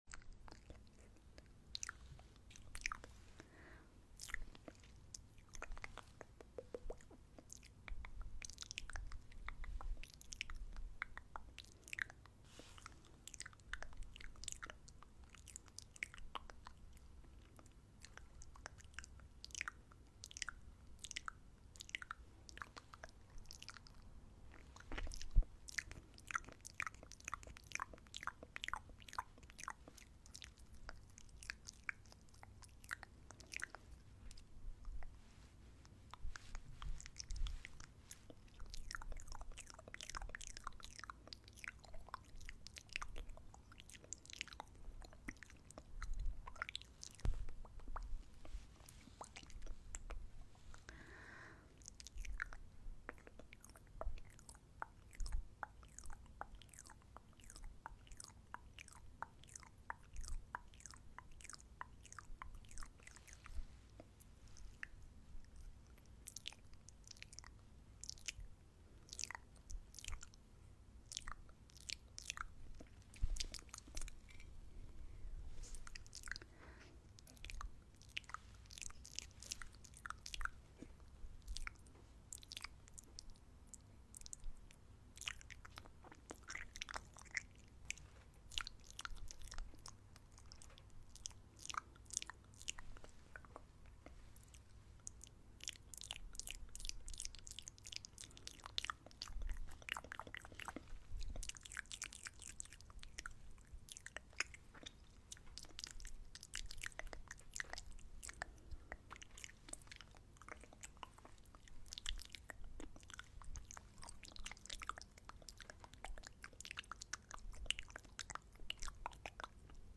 Asmr Saliva Paint, Edible Food Sound Effects Free Download
asmr saliva paint, edible food